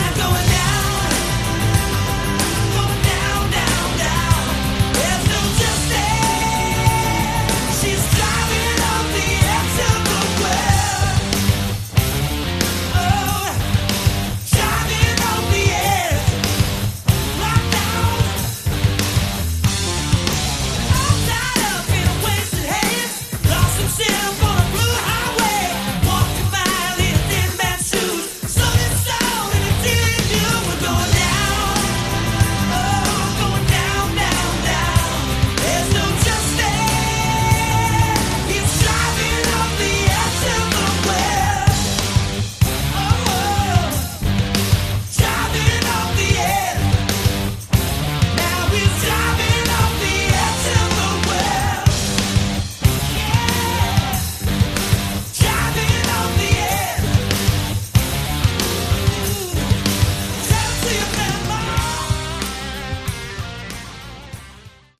Category: Hard Rock
bass, backing vocals
guitars, backing vocals
lead and backing vocals, drums